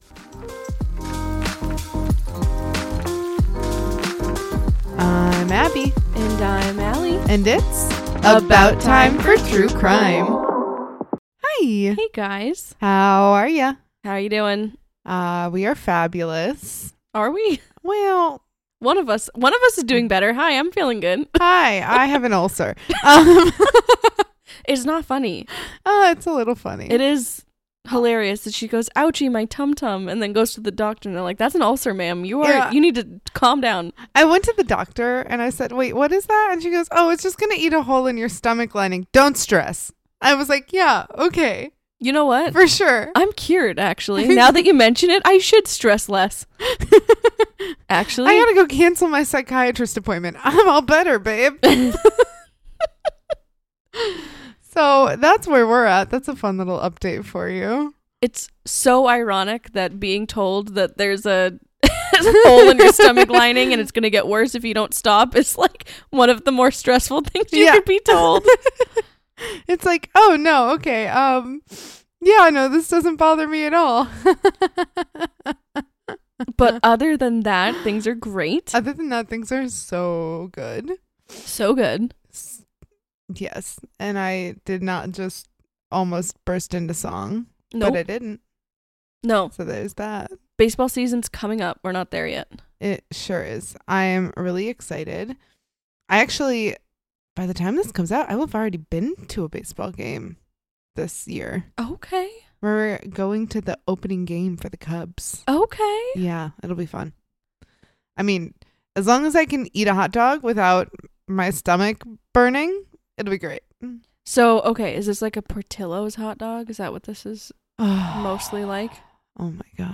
Episode 152: Teal Swan: Part 4 RoundtableIn our fourth and final episode in our Teal Swan series, we have a roundtable discussion about Teal, her followers, her claims, her practices, the impact and the ethical concerns around it all. We discuss cult red flags, green flags, and the feedback from the private investigator that Teal hired.